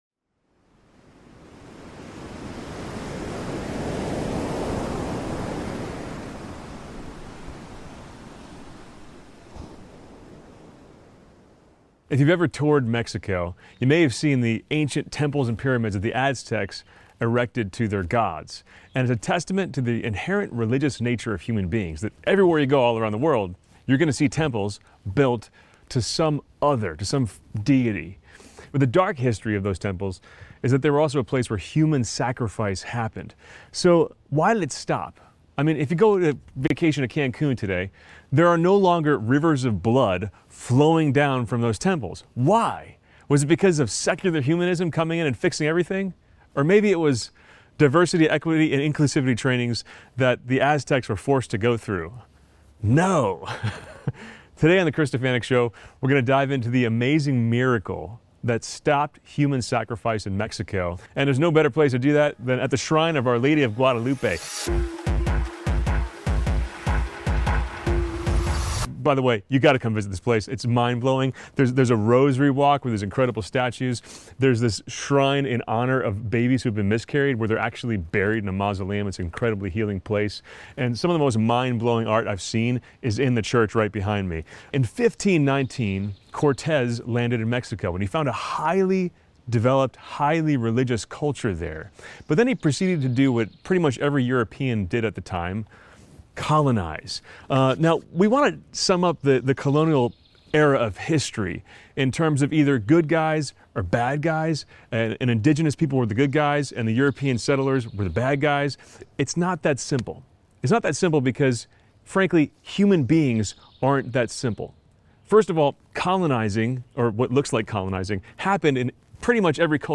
You guys, I am so excited to share this episode with you, that I filmed at the Shrine of Our Lady of Guadalupe!